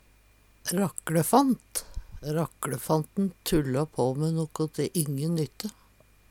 rakklefant - Numedalsmål (en-US)